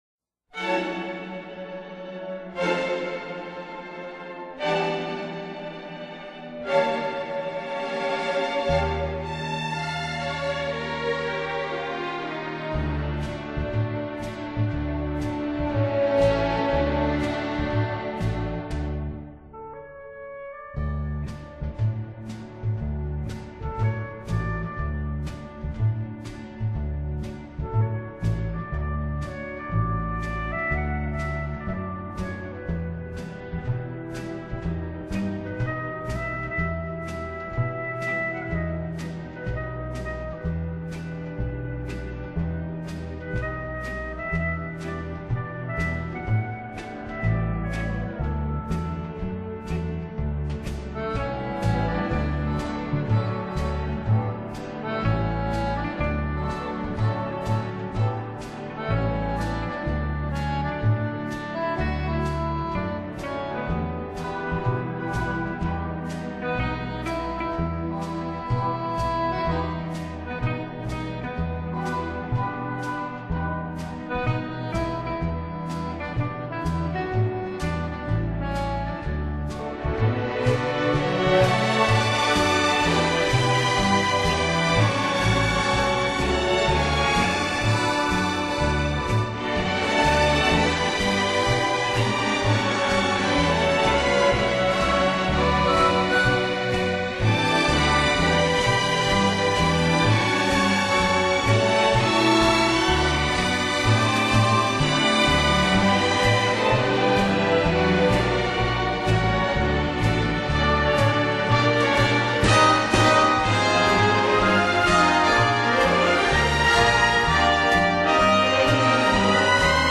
音乐类型：古典
专辑语言：纯音乐